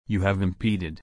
/ɪmˈpiːd/